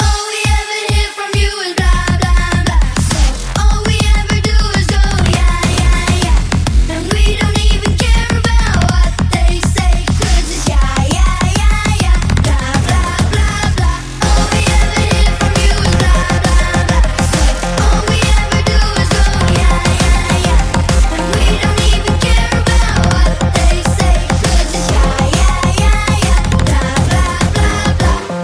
Genere: pop, house, deep, club, edm, remix